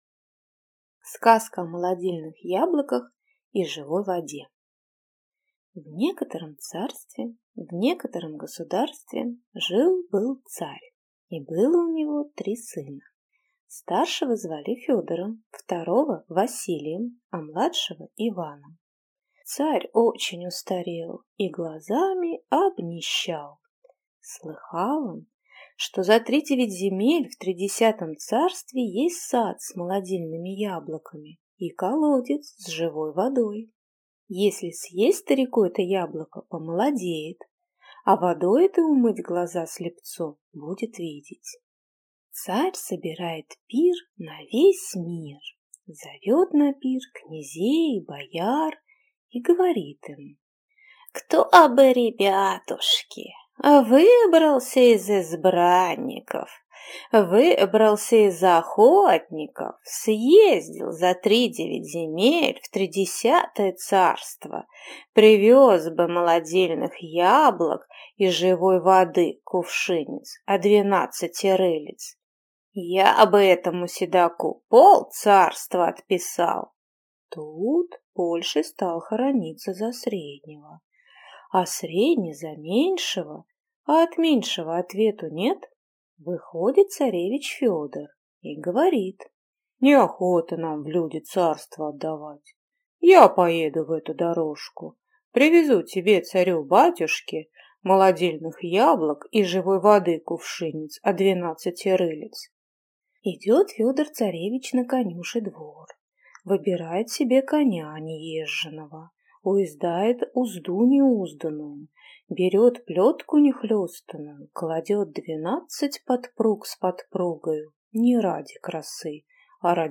Аудиокнига Сказка о молодильных яблоках и живой воде | Библиотека аудиокниг